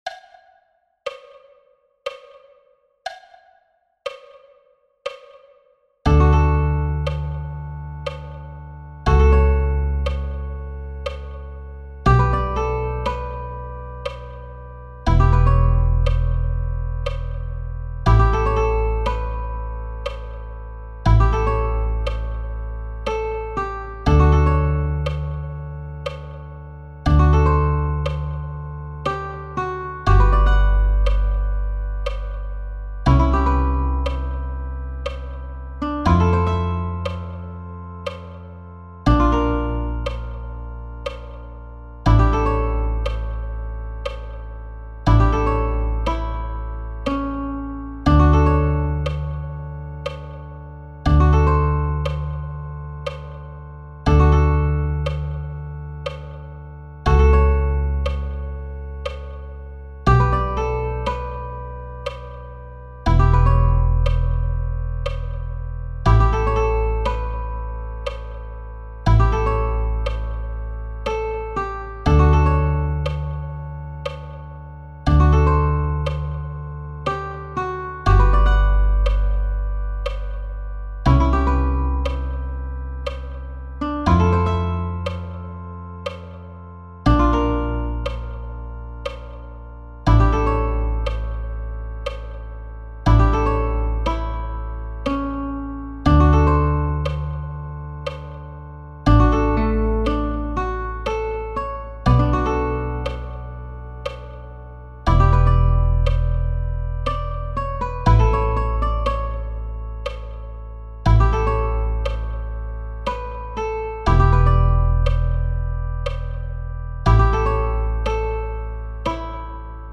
.mp3 File (slow version)